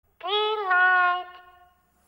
Зеленый свет голос куклы